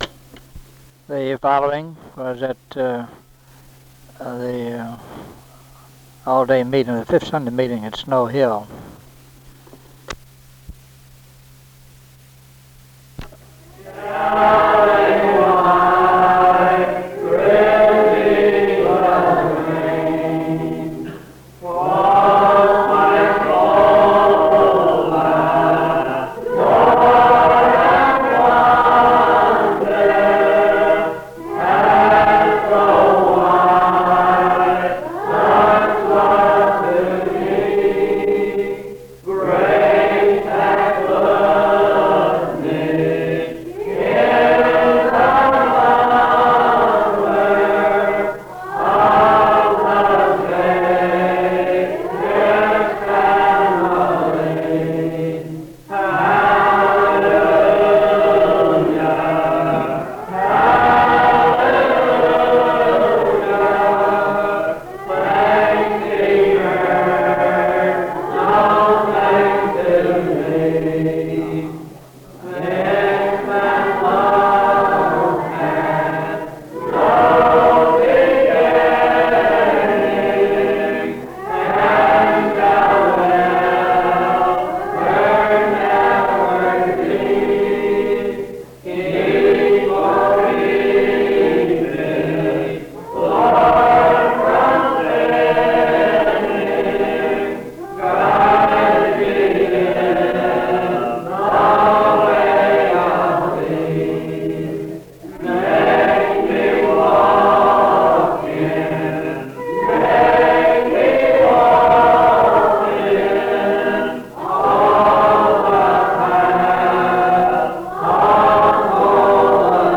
Singing
From the All-day Meeting at Snow Hill Church on a fifth Sunday